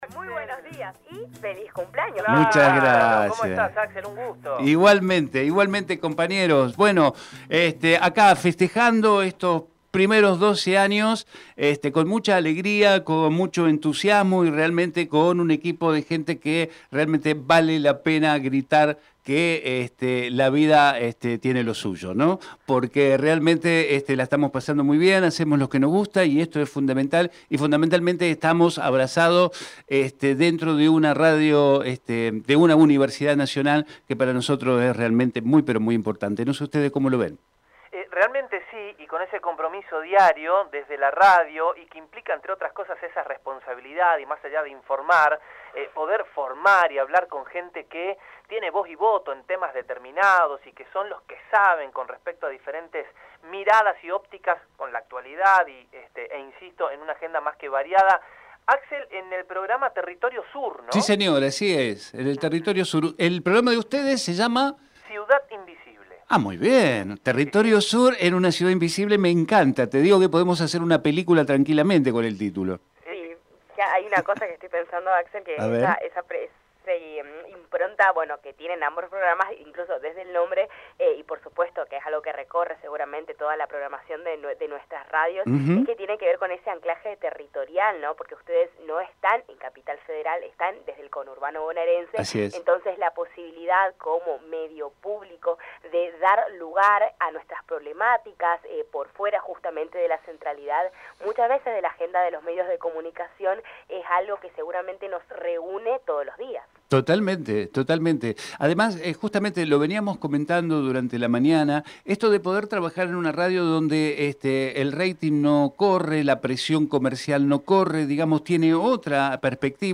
TERRITORIO SUR - DUPLEX CON RADIO UNIVERSIDAD DEL NORDESTE Texto de la nota: Compartimos con ustedes la entrevista realizada con la Radio Universidad del Nordeste.